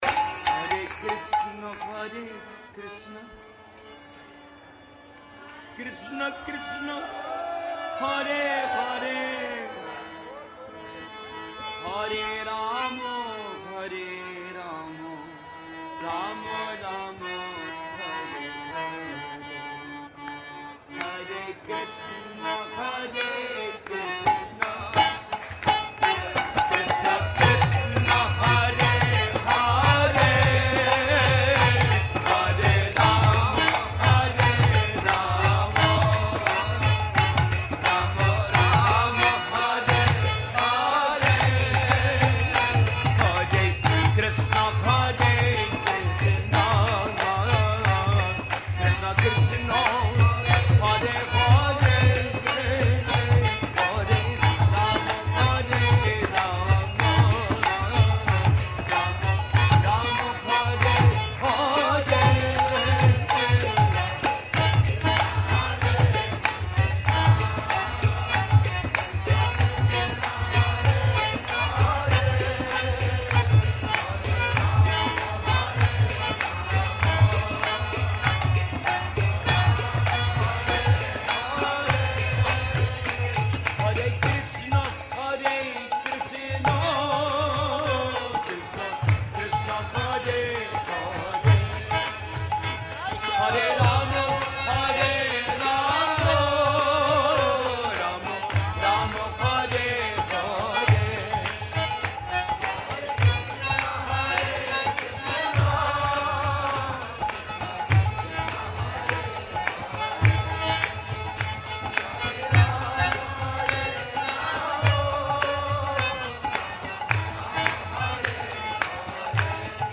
Temple kirtans